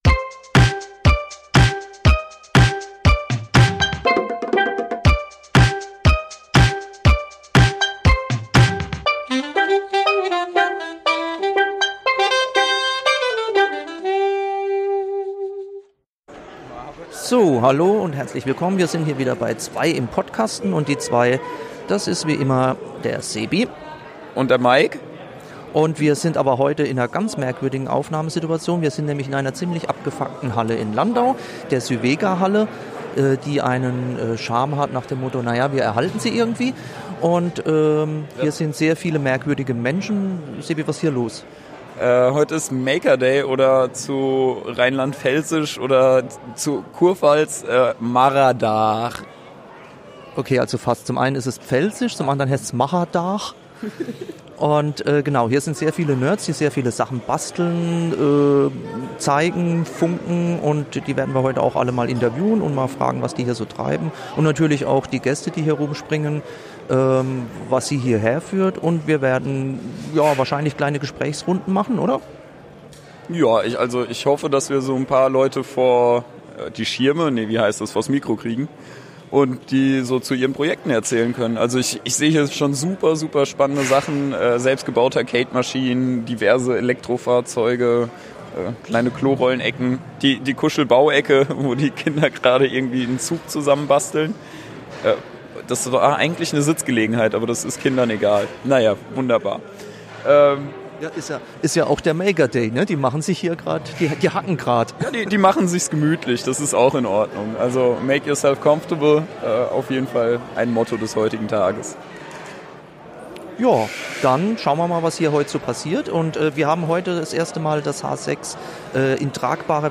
Eine Makerfair in Landau: tolle Leute, Projekte und Stimmung!